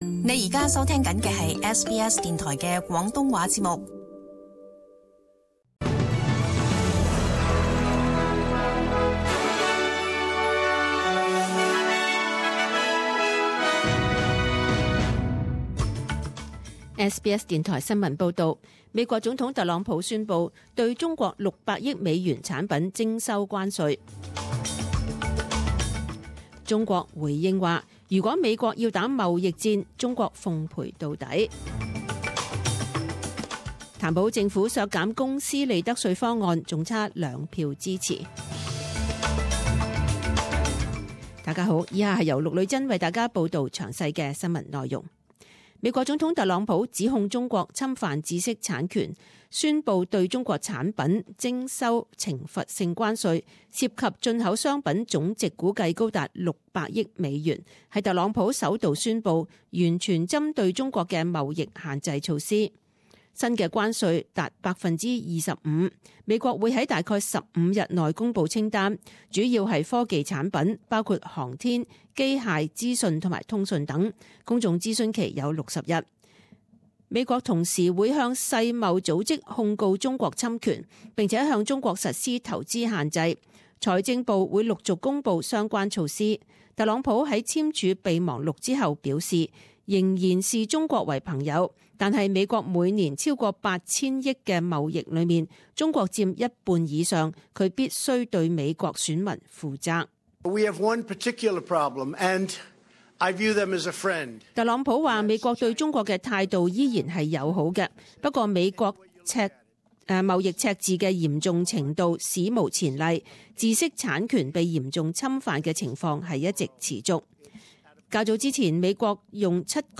SBS中文新闻 （三月二十三日）
请收听本台为大家准备的详尽早晨新闻。